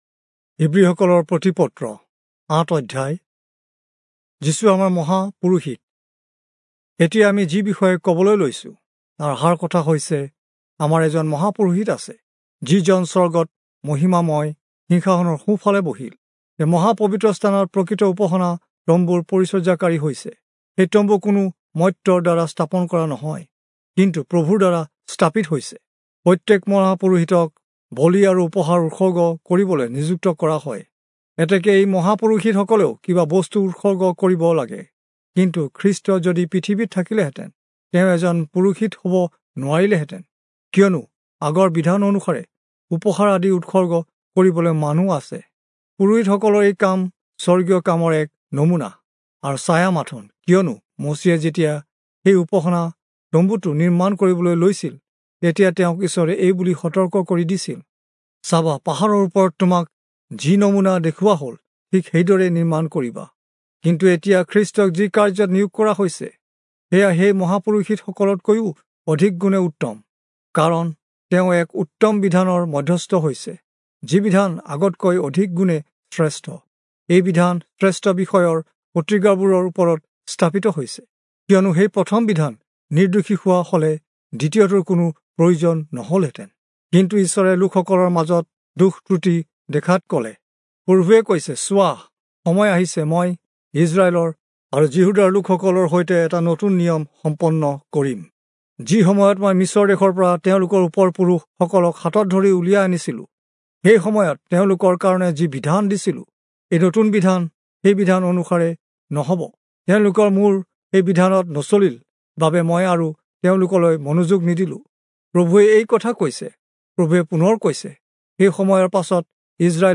Assamese Audio Bible - Hebrews 7 in Nlv bible version